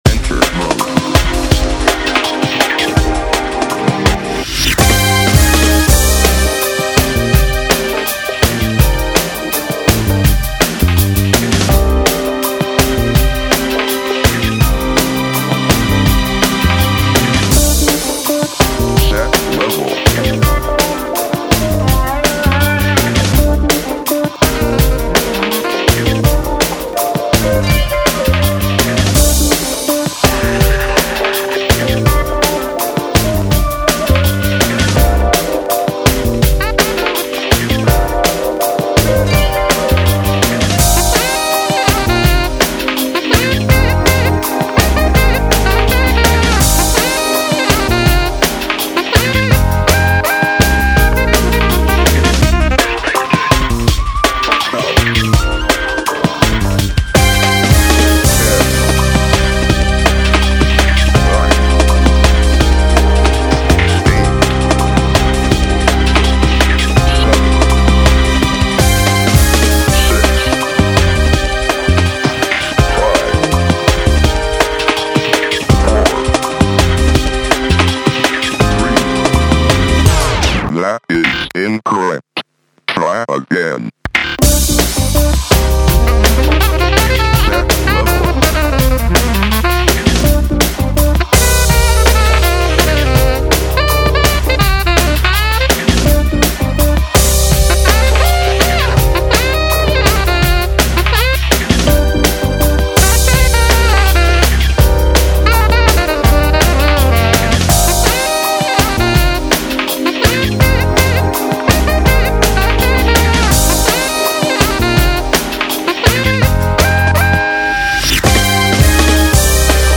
"Dying To Live." (instrumental)